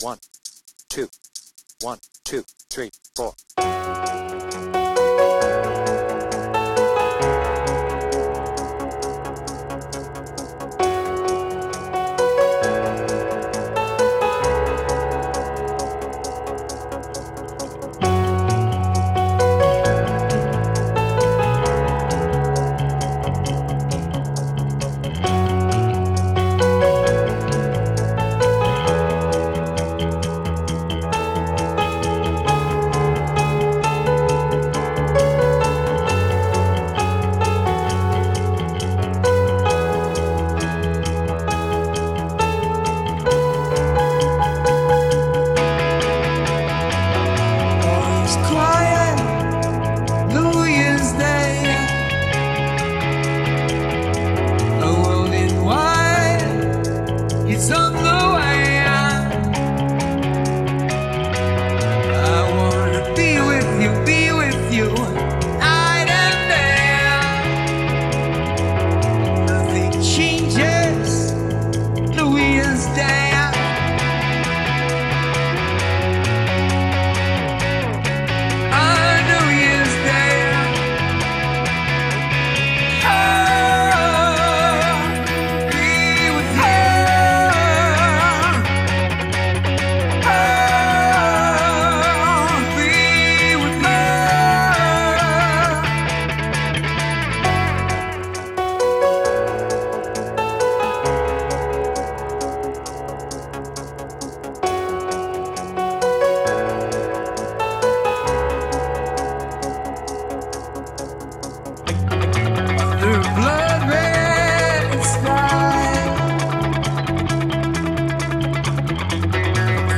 BPM : 133
Tuning : D
With vocals